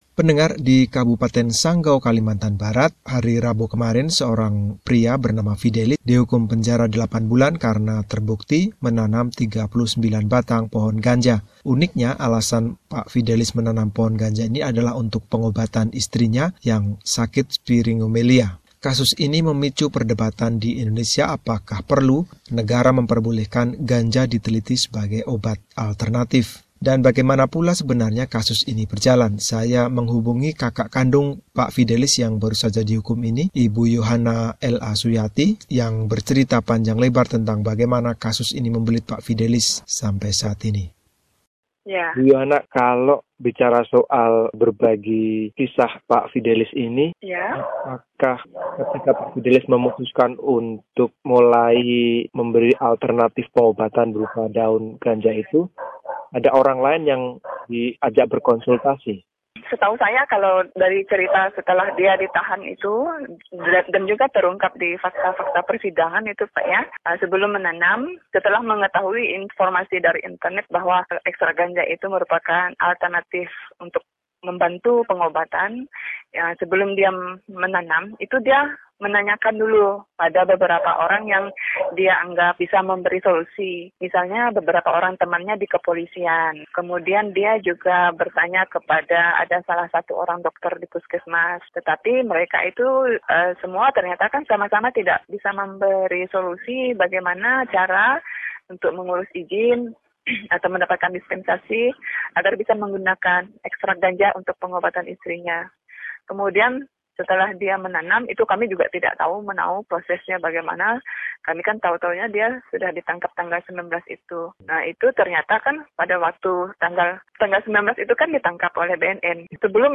Wawancara dengan ibu